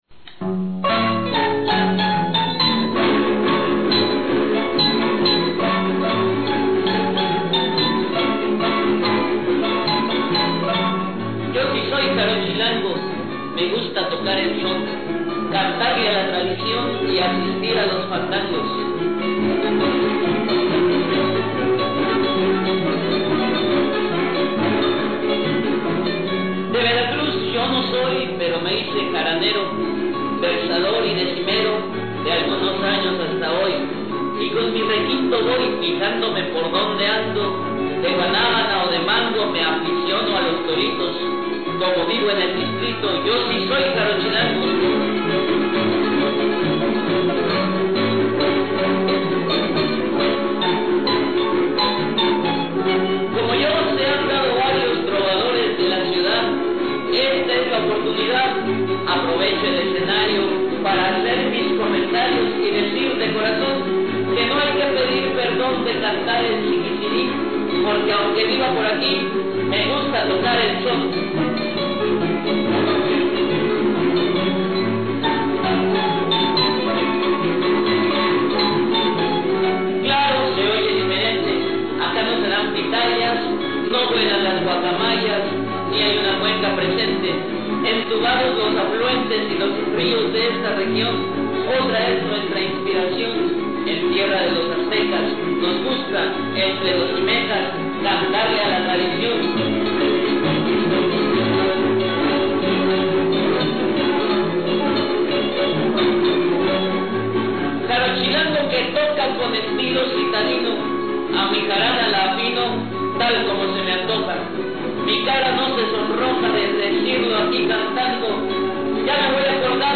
"Los chaneques" estábamos con ganas de decir cosas: nuestros motivos para permanecer "cerca del fogón" del son jarocho, narrar un poco lo que sentíamos era nuestro quehacer en esas tierras "ajenas". Y bueno, lo dijimos en décima, aquí está la grabación del ensayo previo a dicho Encuentro.